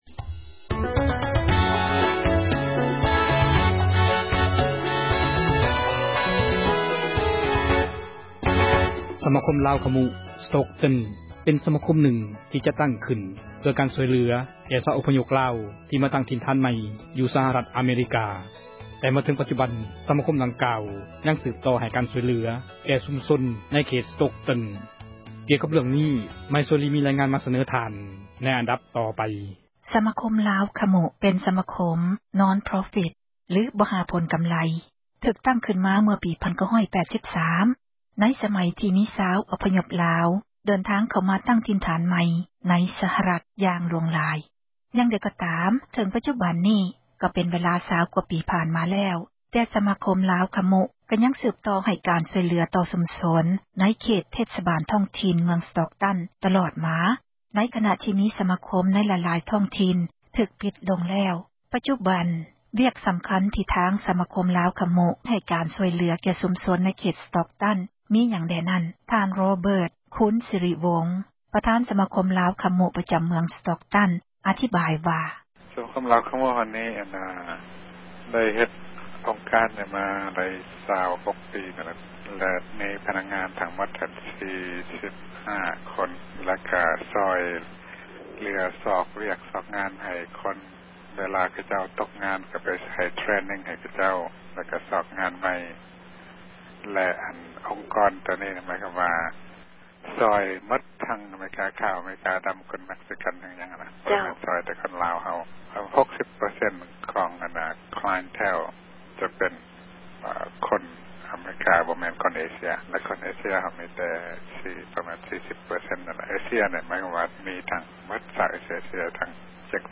ມີຣາຍງານກ່ຽວກັບ ເຣື້ອງນີ້ມາສເນີທ່ານ ໃນອັນດັບຕໍ່ໄປ...